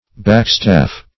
Backstaff \Back"staff`\, n.